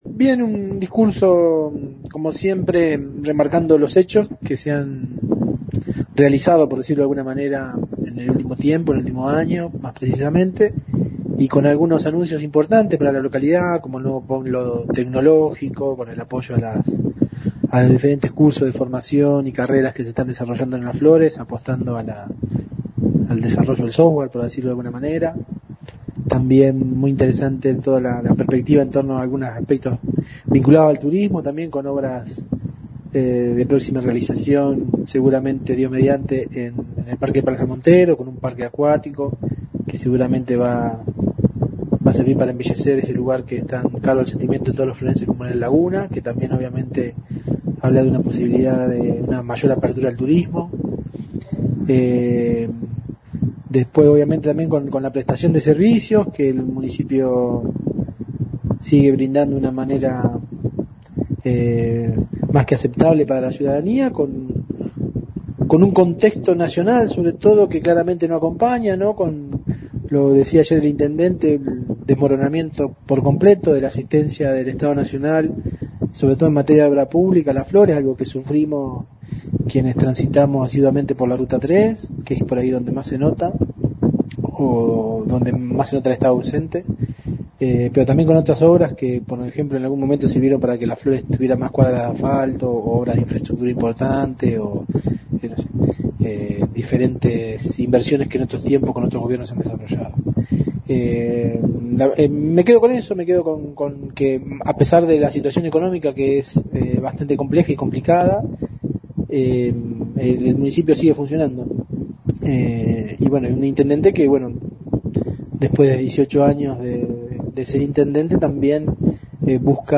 (incluye audios) Luego del tradicional mensaje del jefe comunal quien dejó inaugurado un nuevo período de sesiones ordinarias en el salón «Dr. Oscar Alende», la 91.5 habló con referentes de los tres bloques políticos que conforman el HCD local.
Concejal Leonardo Municoy (Bloque Todos X Las Flores):